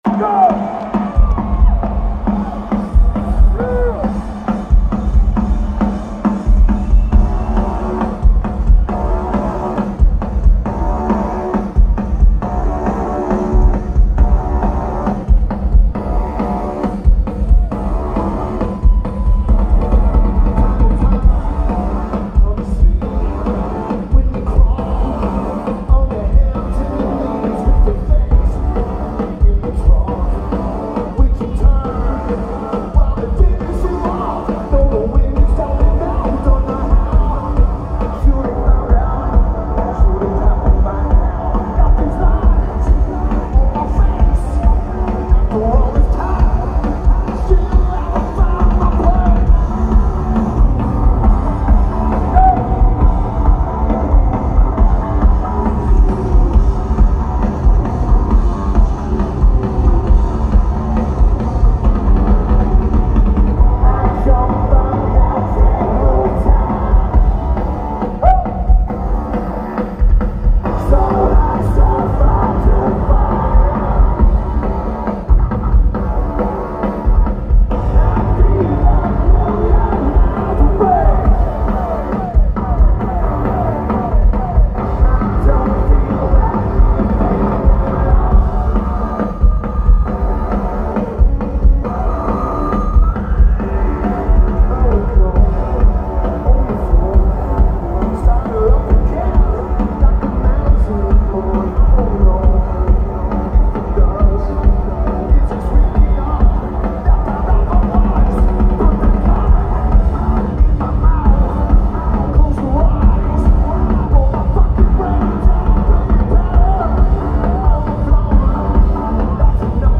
Sleep County Amphitheater
Lineage: Audio - AUD, Unknown Digital Cam Audio